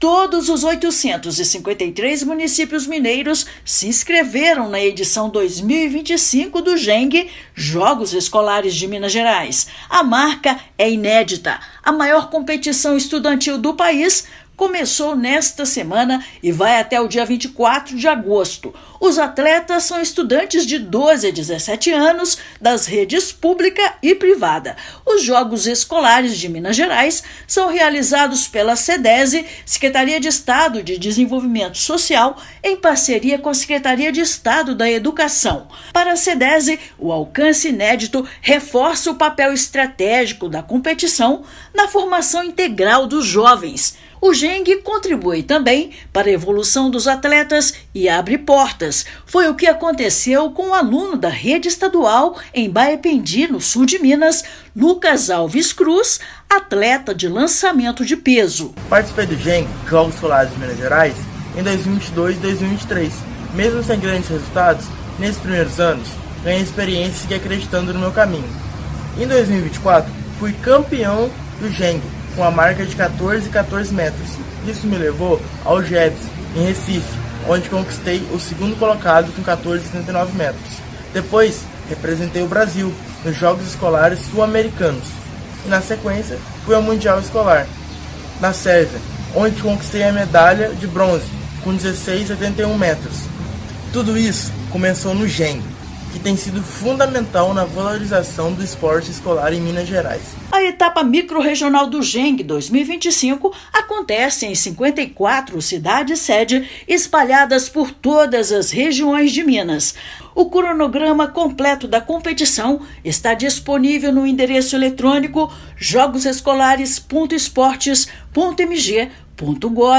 [RÁDIO] Jogos Escolares de Minas abrem edição histórica com 100% dos municípios inscritos